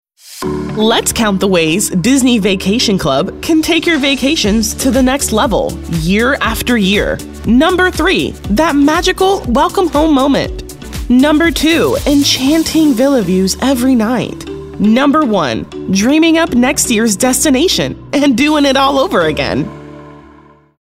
African American, animated, announcer, confident, cool, corporate, friendly, genuine, girl-next-door, inspirational, millennial, narrative, smooth, storyteller, upbeat, warm